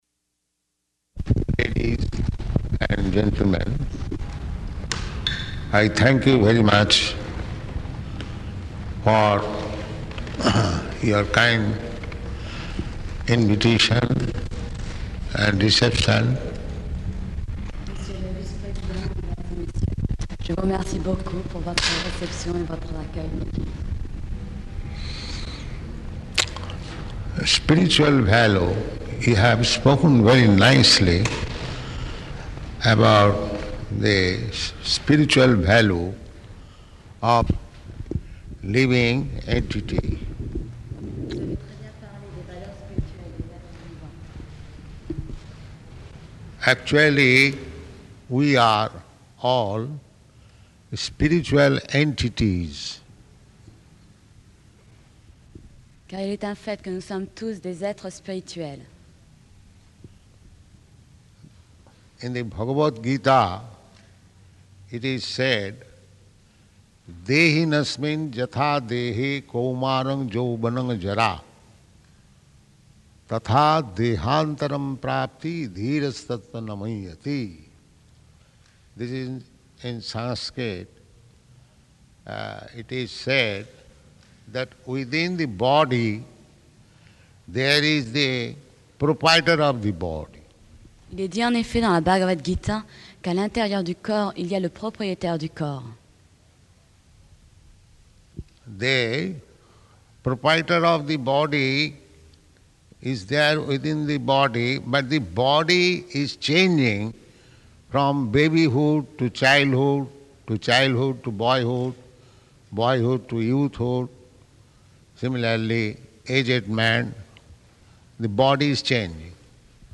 Lecture at Hotel de Ville --:-- --:-- Type: Lectures and Addresses
Location: Paris
[translated simultaneously into French